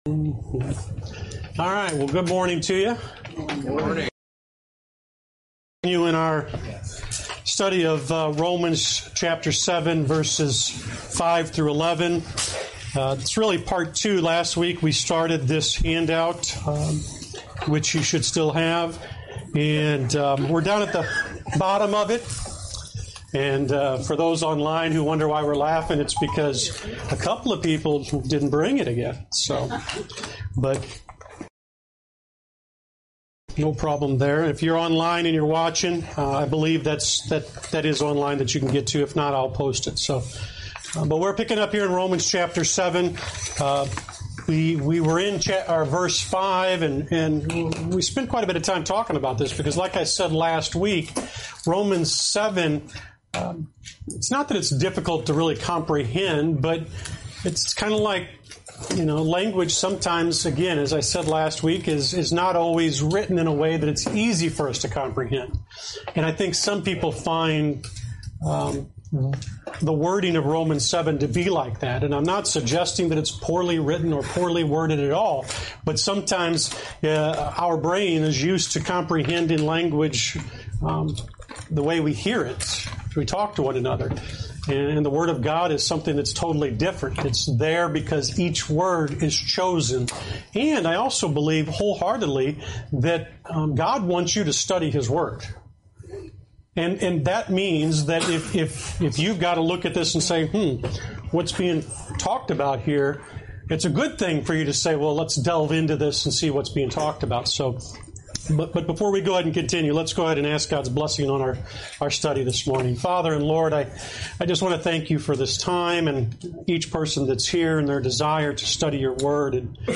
Lesson 37: Romans 7:7-11